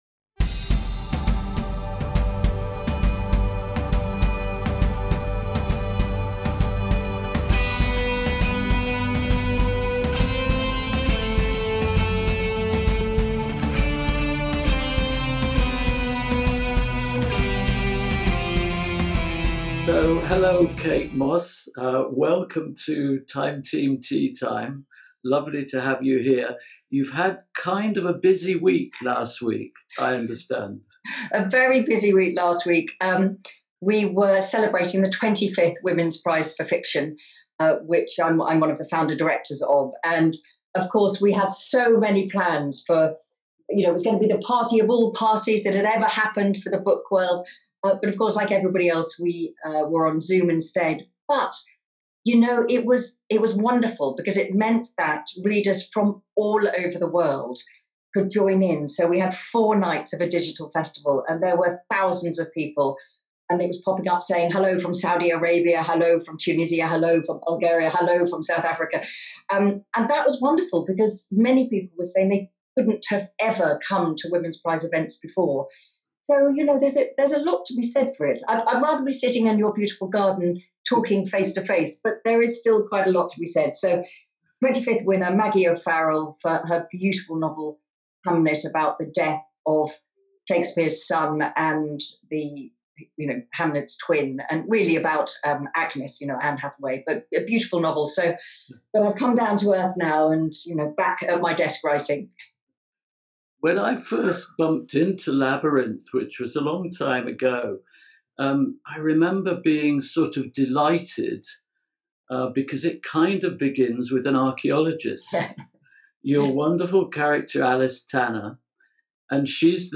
We're joined by number one bestselling author of Labyrinth, Kate Mosse. In the first instalment of a revealing two-part interview, Kate discusses her involvement in the Women's Prize for Fiction (this year won by Maggie O'Farrell for Hamnet), her love of the Languedoc region of France and the inspiration behind her Carcasonne trilogy, which also includes Citadel and Sepulchre.